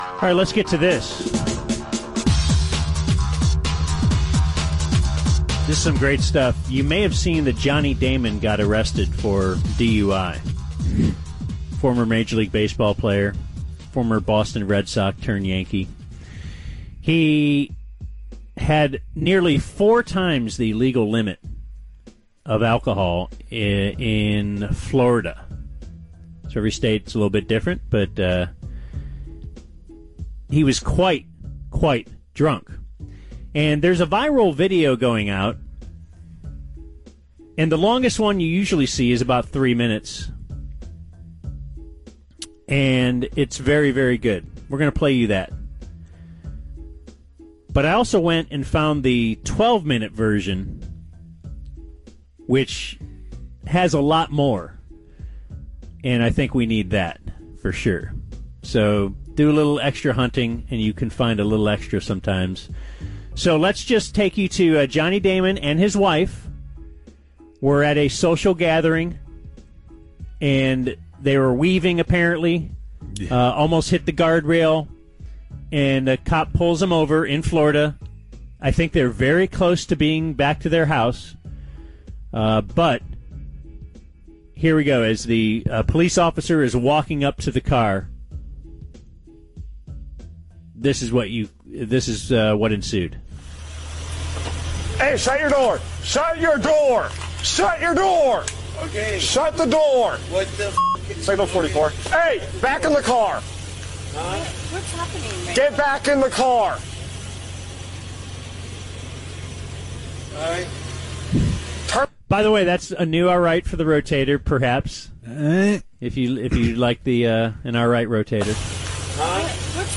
Having some fun with the wheels-completely-off audio from Johnny Damon and wife DUI arrest.